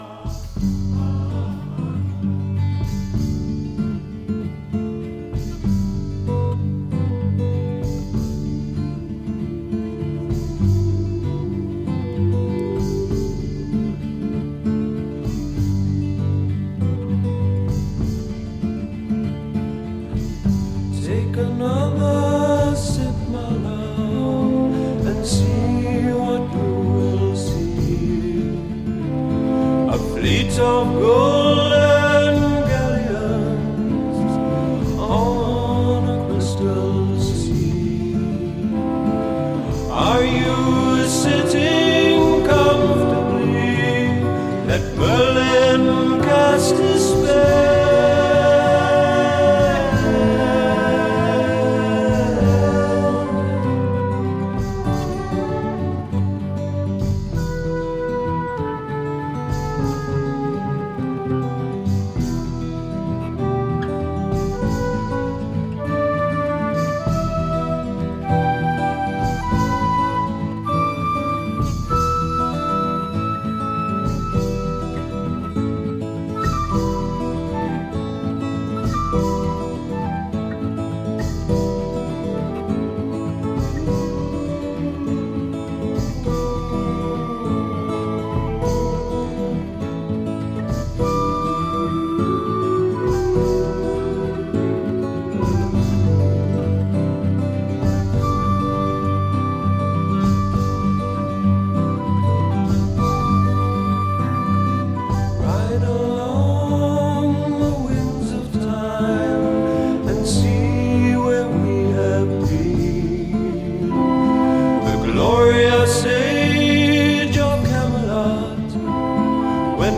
pastoral, flute-and-mellotron infused progressive rock